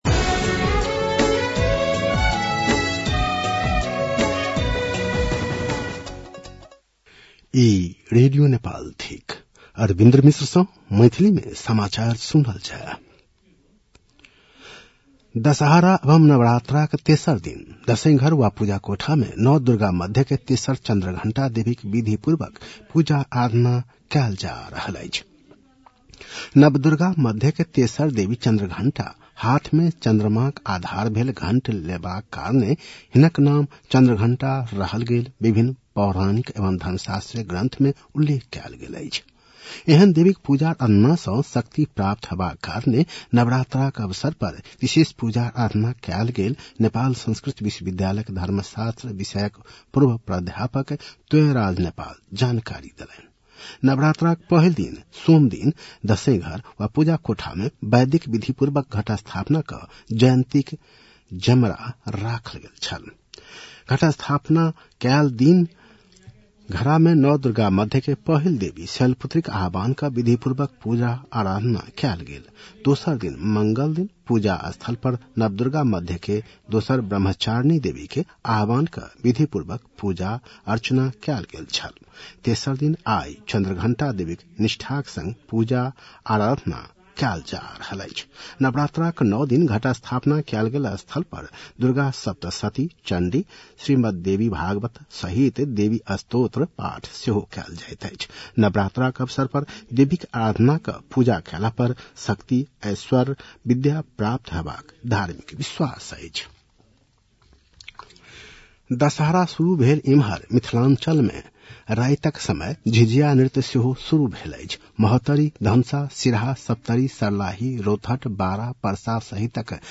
मैथिली भाषामा समाचार : ८ असोज , २०८२